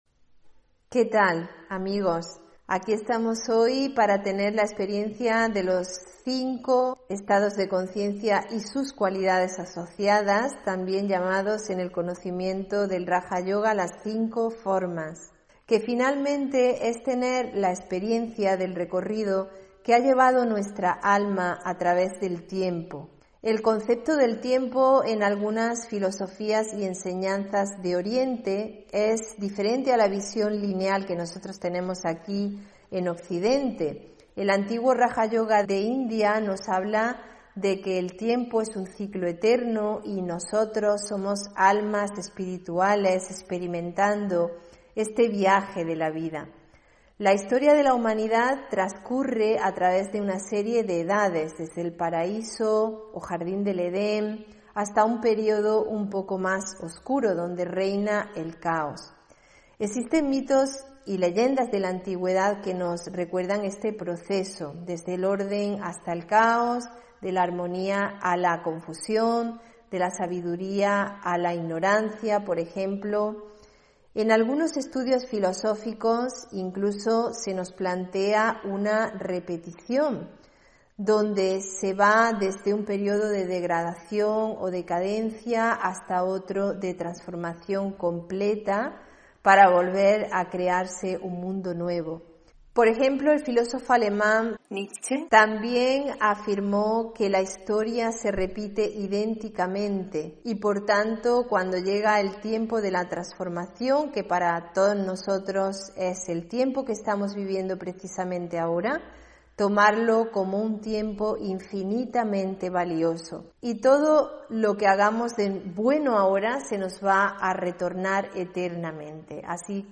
Meditación y conferencia: 5 estados de conciencia (15 Febrero 2022)